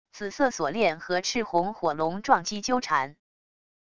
紫色锁链和赤红火龙撞击纠缠wav音频